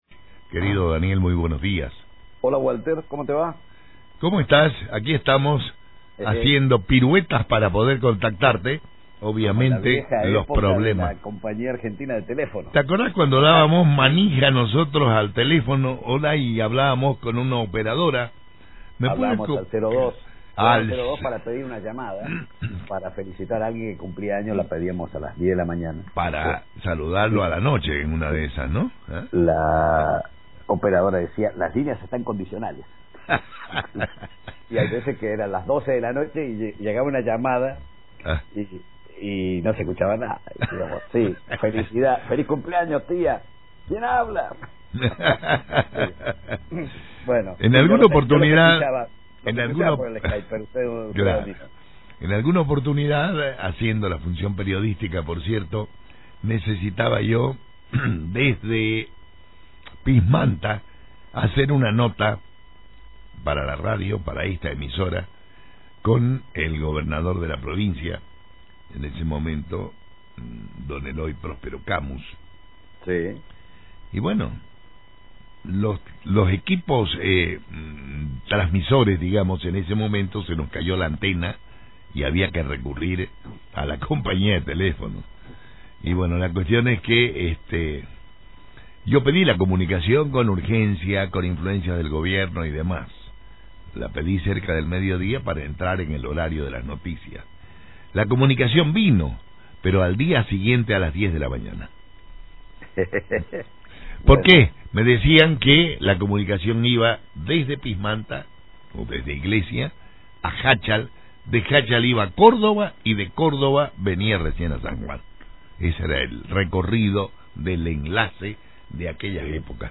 En el podcast siguiente, la conversación de los dos periodistas en el programa "Aferrarse a la vida" por LV5 Radio Sarmiento.